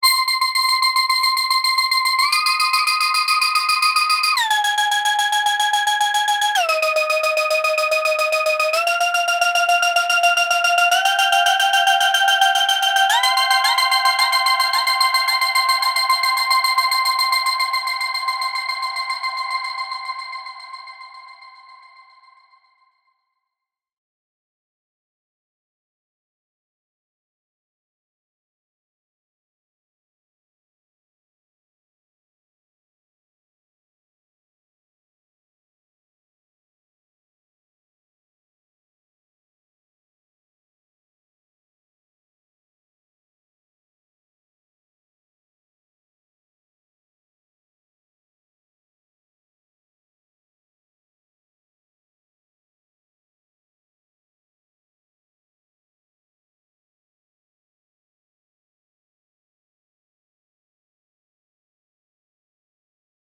多彩なキャラクターと高度な制御を備えたポリリズム・フィードバックディレイ
Recirculate | Synth | Preset: Big Lead Quarter
Synth-Big-Lead-Quarter.wav